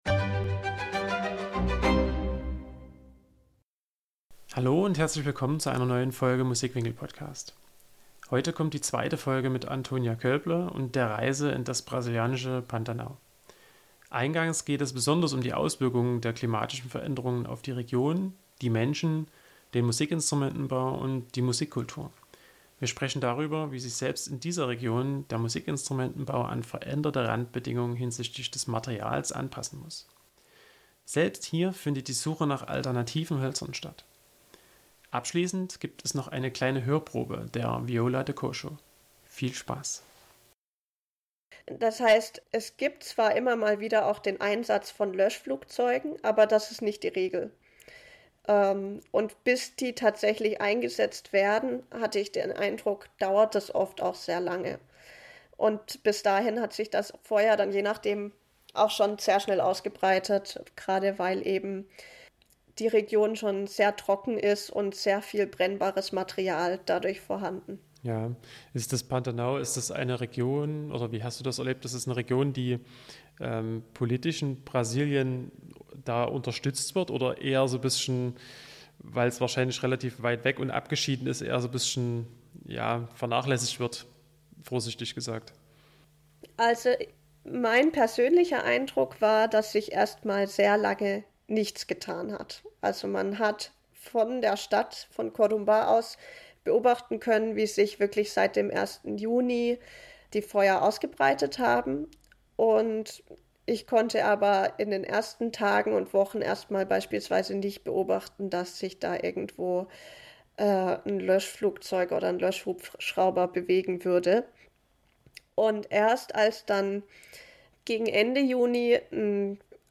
Eingangs geht es besonders um die Auswirkungen der klimatischen Veränderungen auf die Region, die Menschen, den Musikinstrumentenbau und die Musikkultur. Wir sprechen darüber, wie sich selbst in dieser Region der Musikinstrumentenbau an veränderte Randbedingungen hinsichtlich des Materials anpassen muss. Selbst hier findet die Suche nach alternativen Hölzern statt. Abschließend gibt es noch eine kleine Hörprobe von der Viola de Cocho.